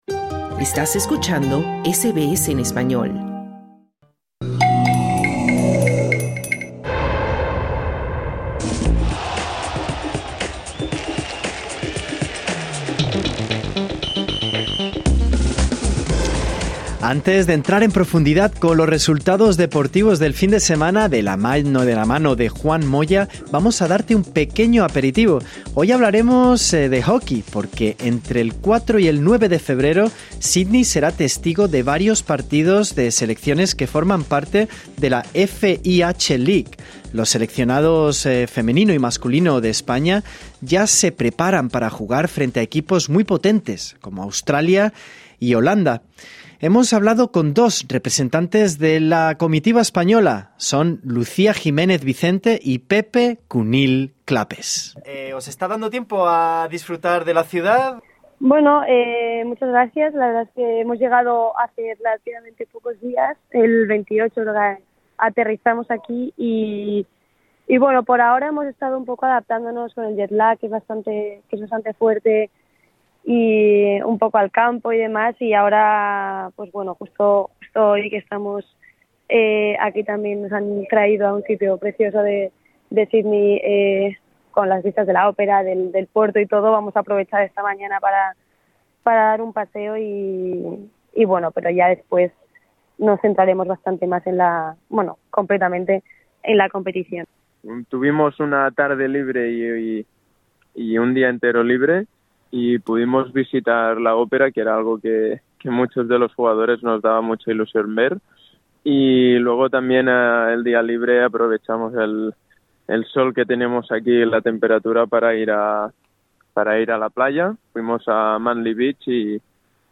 Entre el 4 y el 9 de febrero, Sídney será testigo de varios partidos de selecciones que forman parte de la FIH Pro League. Los seleccionados femenino y masculino de España ya se preparan para jugar frente a equipos muy potentes como Australia y Holanda. Hemos hablado con dos representantes de la comitiva española